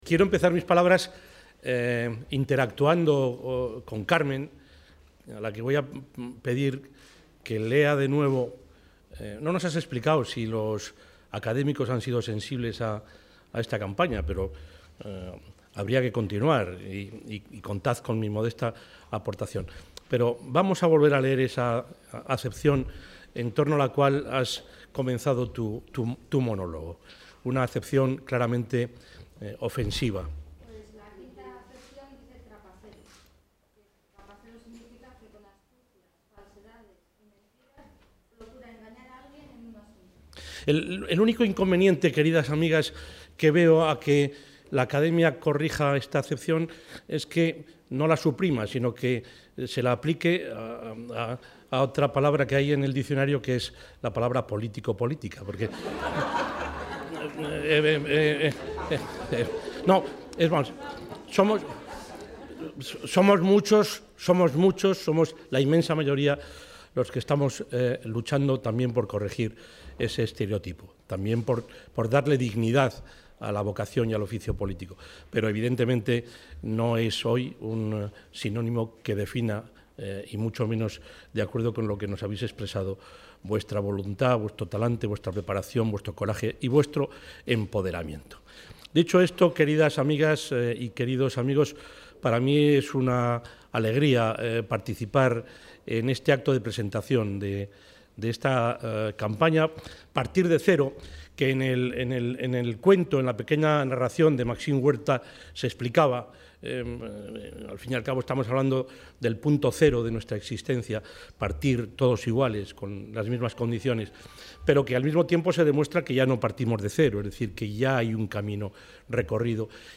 El presidente de la Junta, Juan Vicente Herrera ha participado en la presentación en Castilla y León de la campaña ‘Partir...
Intervención del presidente de la Junta de Castilla y León.